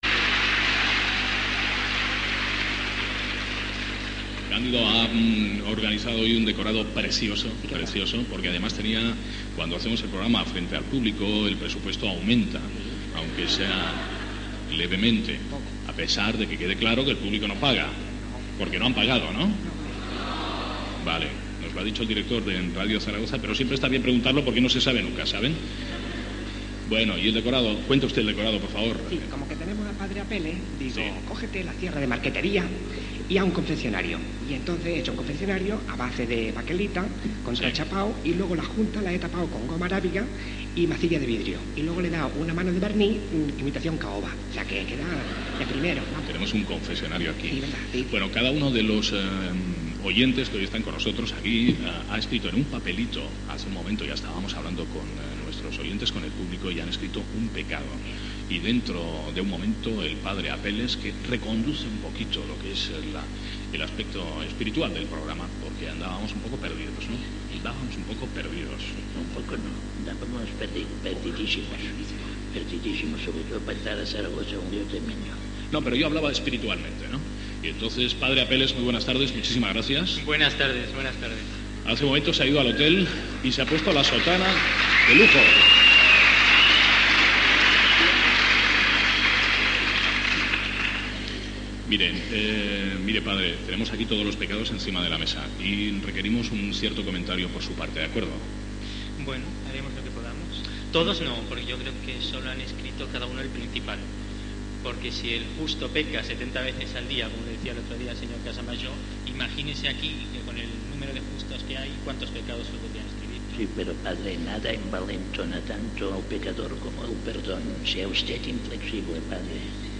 Fragment d'una emissió especial des de Saragossa, cara al públic.
Entreteniment
Sardà, Xavier